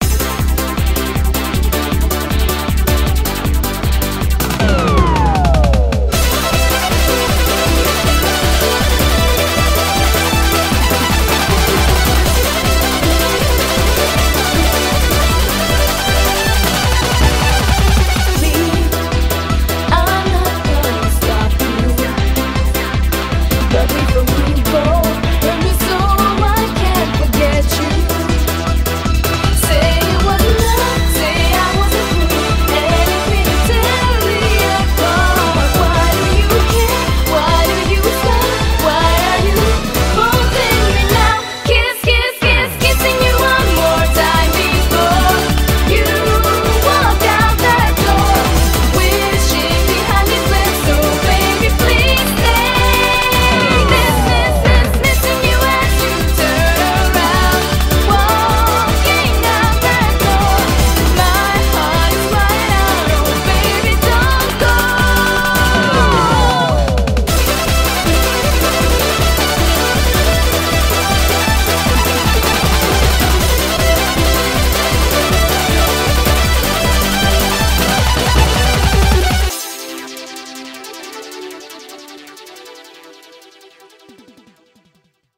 BPM157
Audio QualityMusic Cut